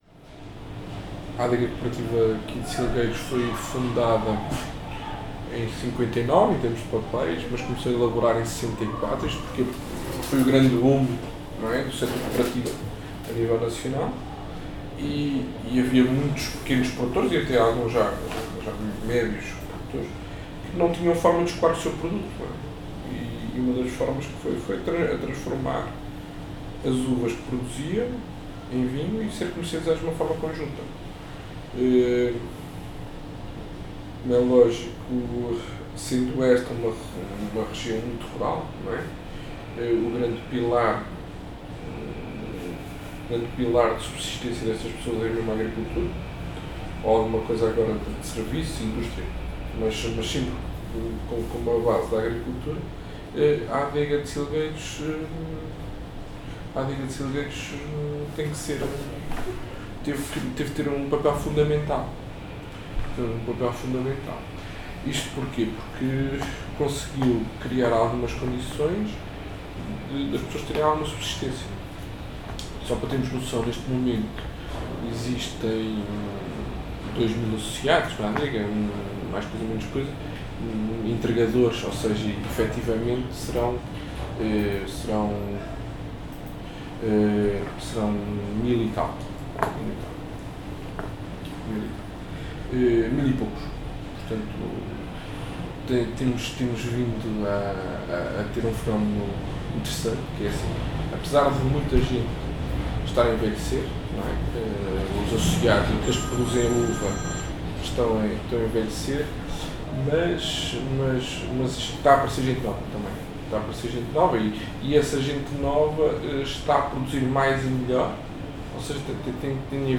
Tipo de Prática: Inquérito Etnográfico
Local: Pindelo de Silgueiros